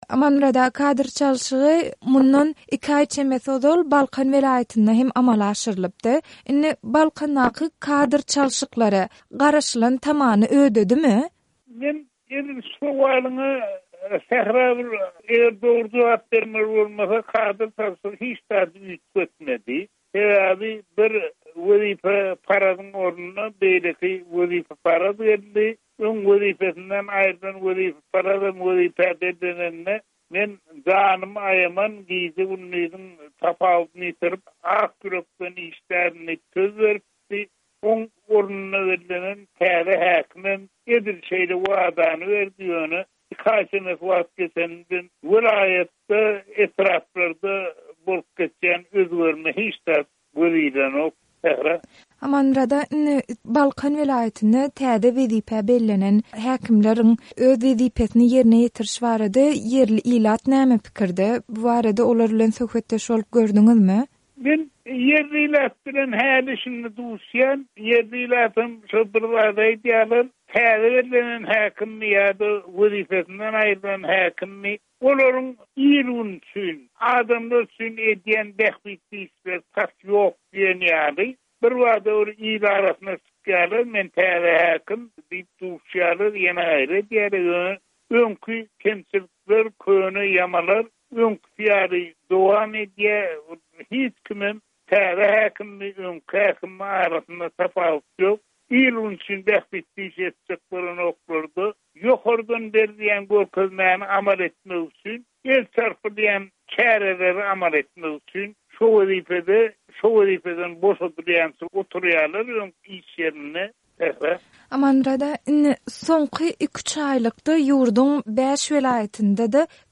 söhbetdeş boldy